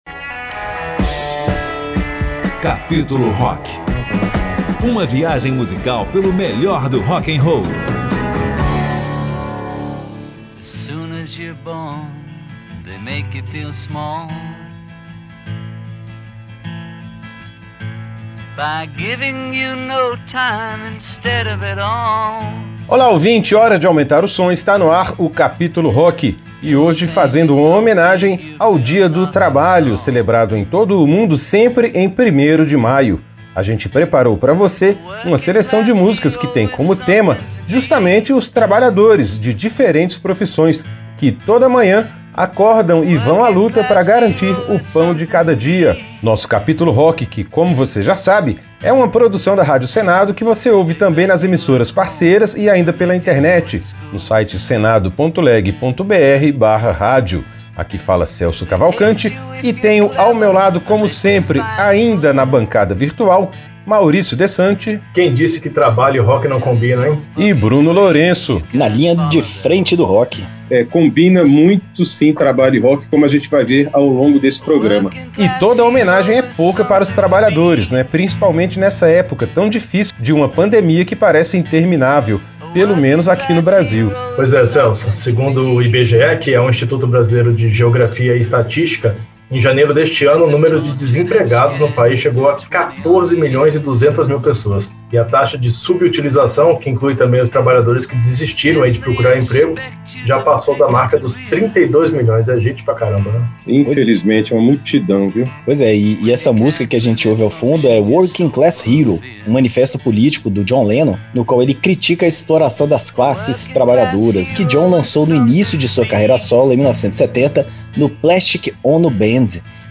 O Capítulo Rock desta semana celebra o Dia do Trabalho, com uma seleção de músicas que enfocam a realidade dos trabalhadores e homenageiam diferentes profissões. A relação entre trabalho e rock’n roll vem de longe, desde os primórdios do blues, que nasceu justamente a partir do cancioneiro entoado pelos escravos nas plantações de algodão no Sul dos Estados Unidos. O programa vai apresentar bandas e artistas de diversos estilos do rock que se inspiraram nessa temática para compor obras que atravessam gerações.